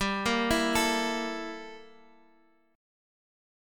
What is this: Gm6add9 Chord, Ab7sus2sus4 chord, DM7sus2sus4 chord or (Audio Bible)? Gm6add9 Chord